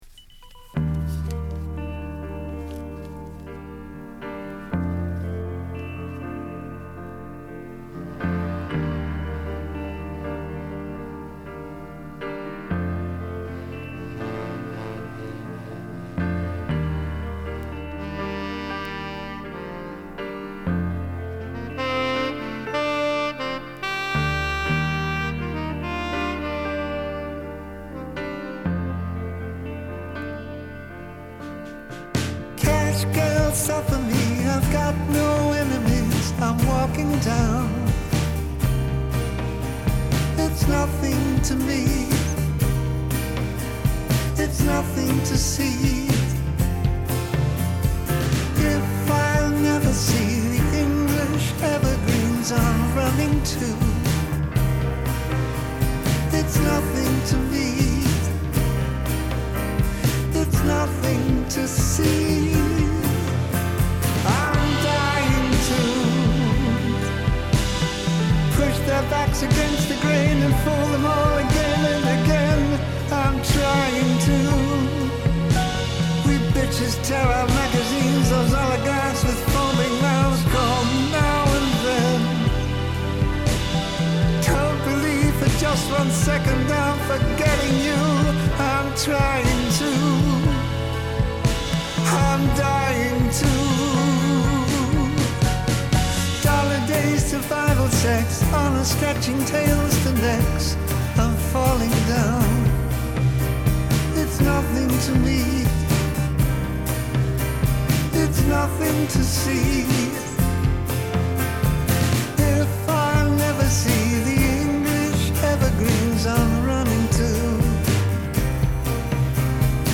It sounded like a man who was dying far from home.